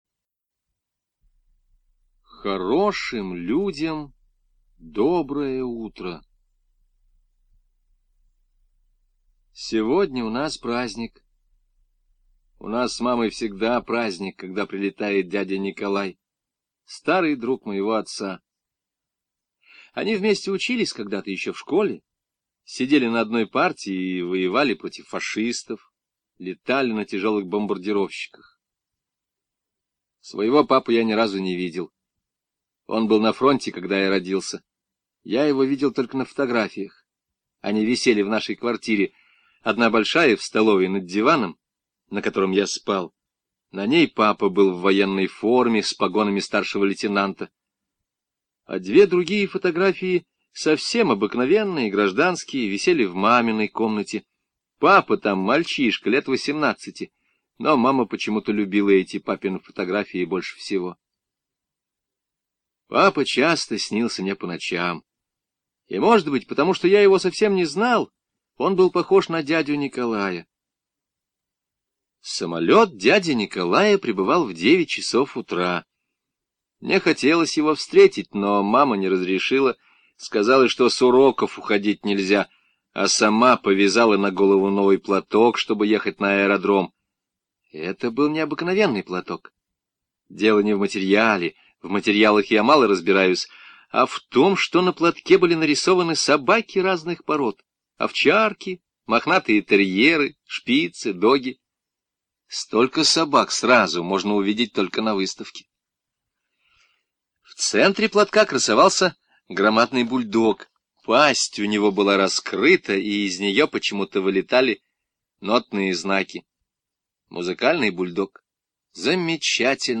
Хорошим людям — доброе утро - аудио рассказ Железникова В.К. Мальчик Толя живет с мамой, его папа был летчиком и погиб на войне в 23 года...